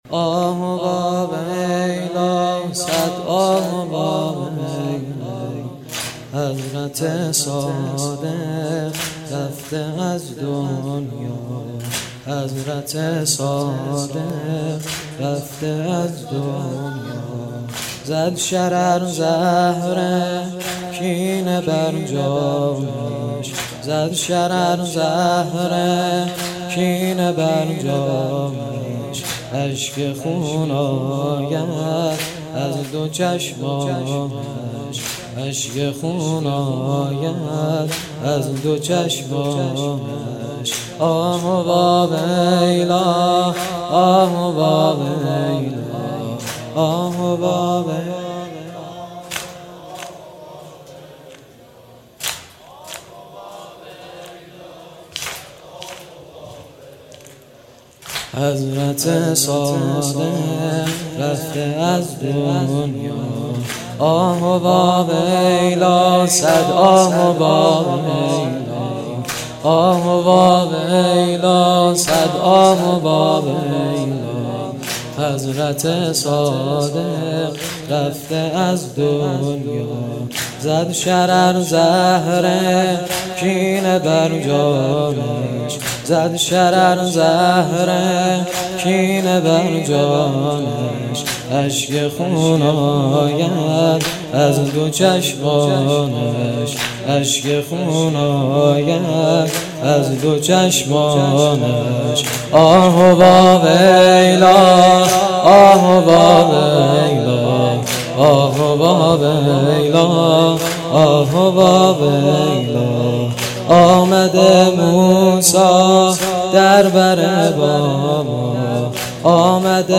شهادت امام صادق علیه السلام-شب سوم